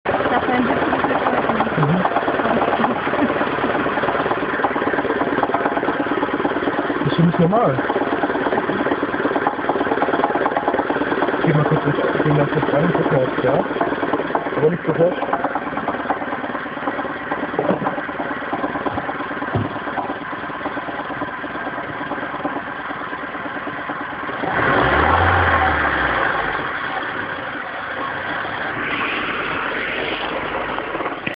» Komisches Klackern aus dem Motorraum Civic EU8... soundfile
Ich habe GENAU das selbe geräusch im Auto, auch einen EU8.
Also des geräusch tritt bei mir nur dann auf wenn ich anhalte, also wenn der motor mit standgas läuft. sobald ich dann mal gas gebe/los fahre ises wieder wech.